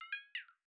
End Call2.wav